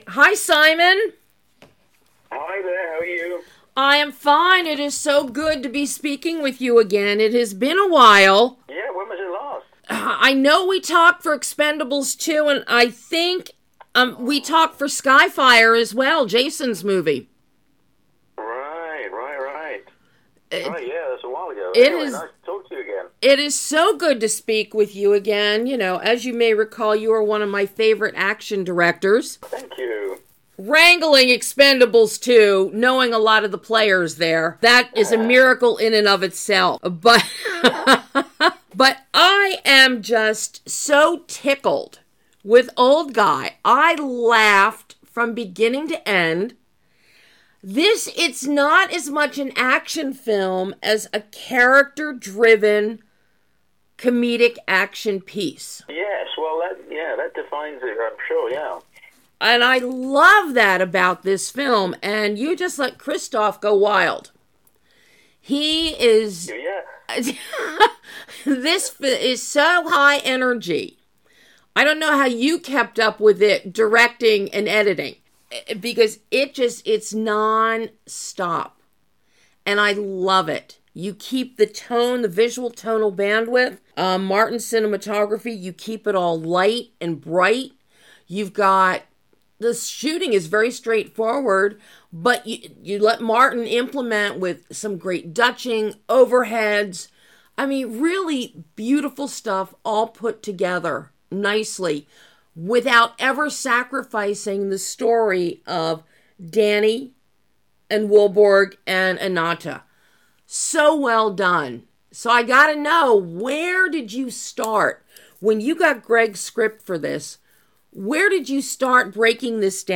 An exclusive interview with director SIMON WEST talking about the action-packed comedy OLD GUY.